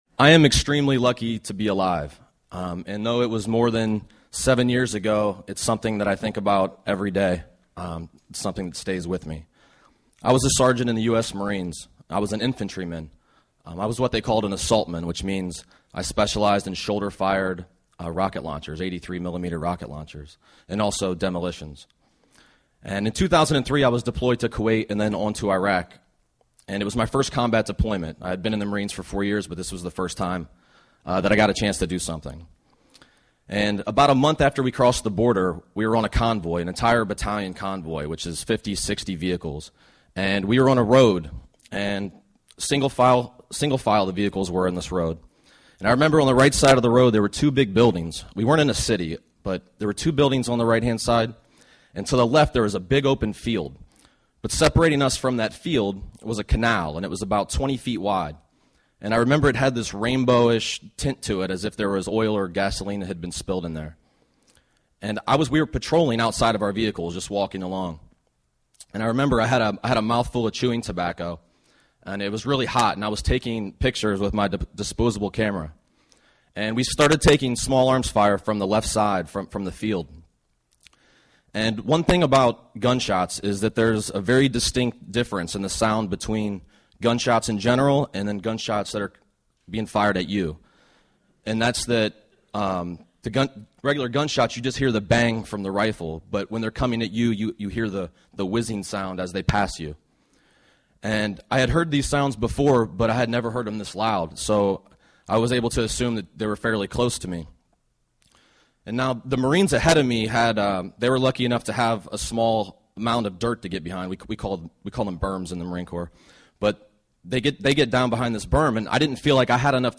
Stories of skirmishes, battles, victories and defeats Seven storytellers get seven minutes each to tell true personal tales of fights waged, won and lost. Music from “2econd N8ture”.